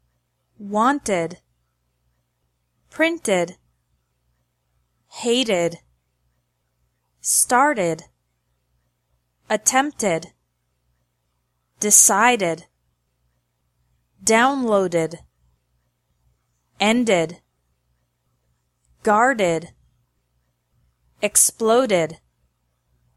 1) -ED is pronounced like ED (with an extra syllable)
After verbs ending in T and D sounds